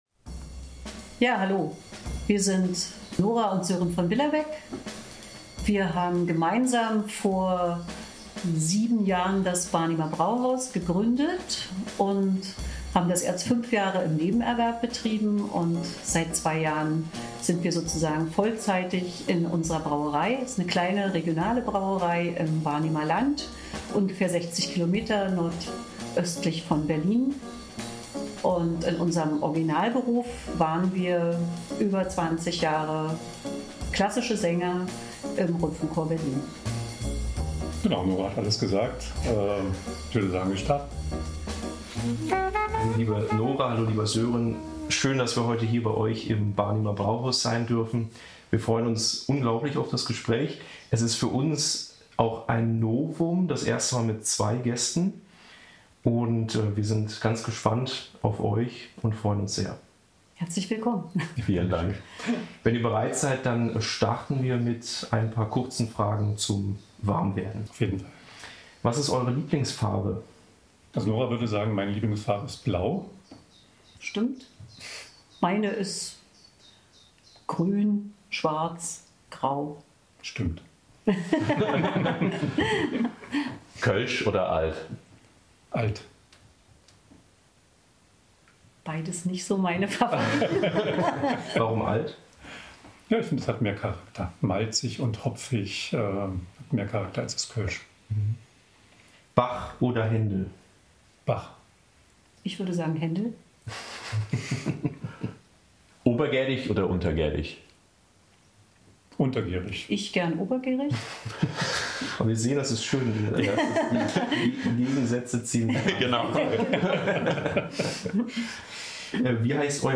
Das Interview wurde am 14. Juni 2024 aufgezeichnet.